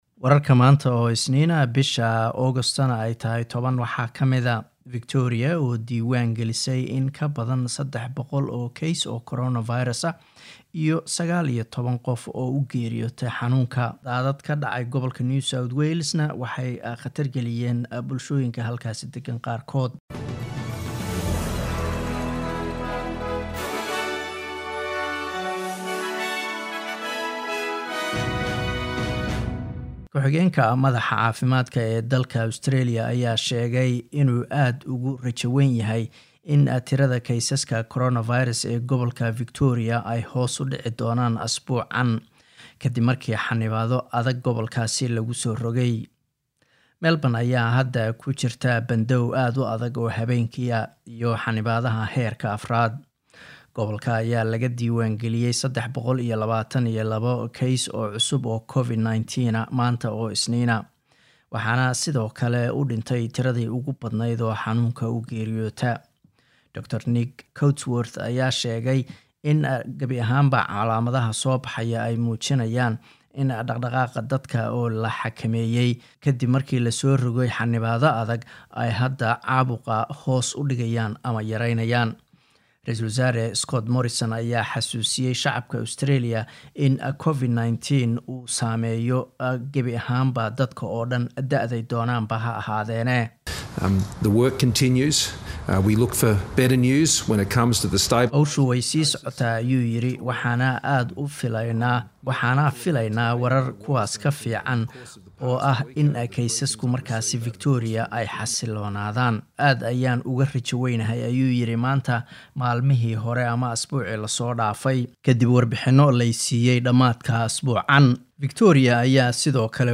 news_10-08_final.mp3